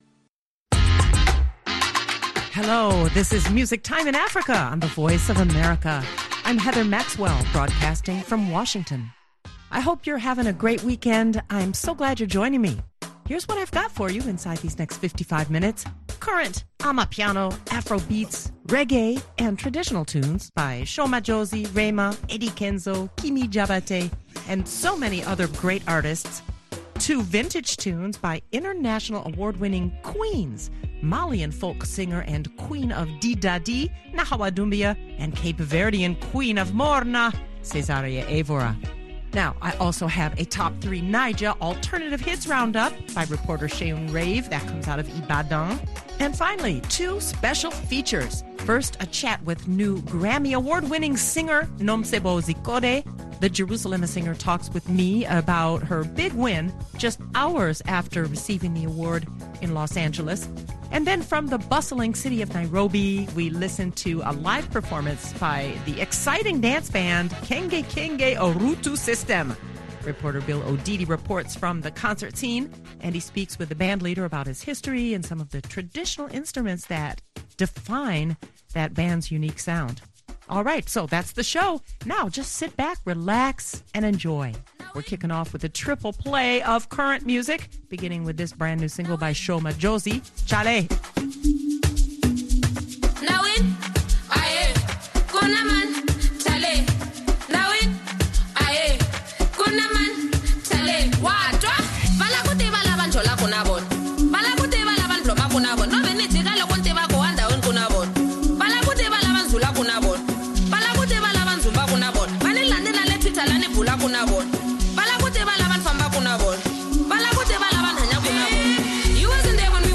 Music Time in Africa is VOA’s longest running English language program. Since 1965 this award-winning program has featured pan African music that spans all genres and generations.